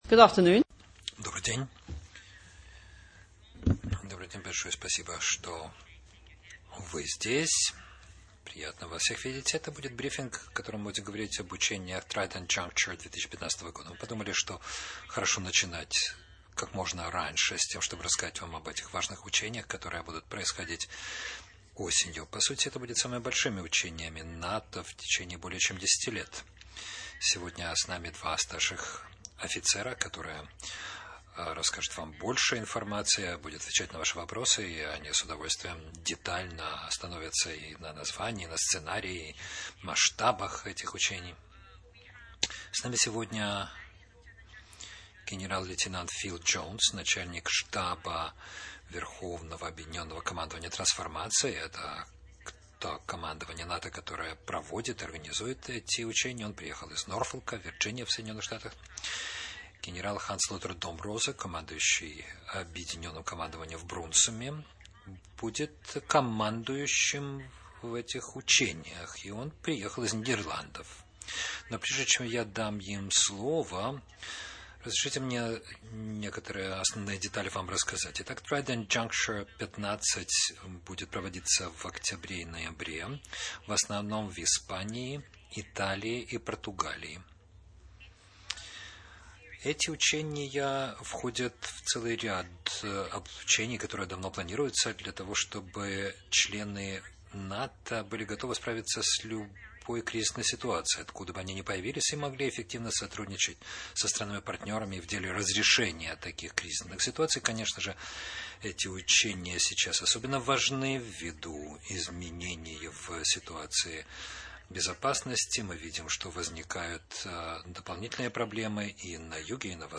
Press briefing on NATO exercise Trident Juncture 2015 by the NATO Spokesperson together with the Commander of JFC Brunssum and the Chief of Staff of Allied Command Transformation